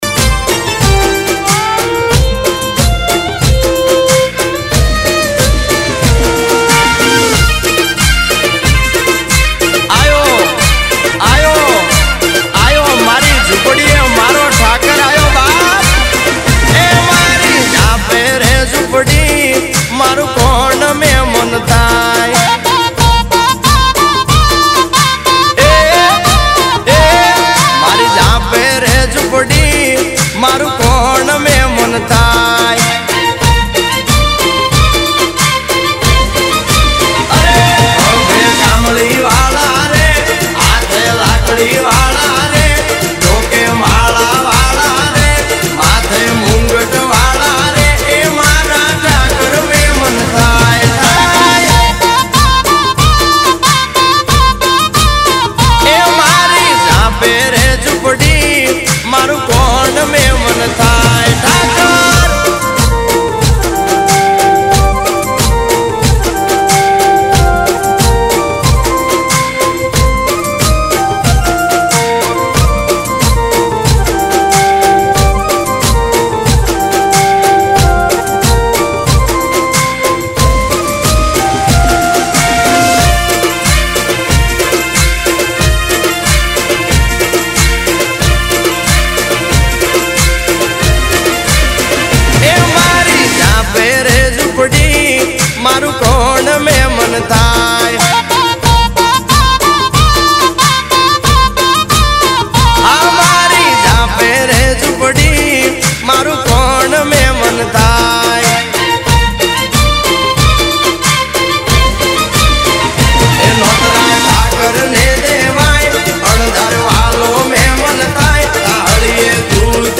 Category: DJ AND BAND (BEND) ADIVASI REMIX